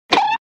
Poke.wav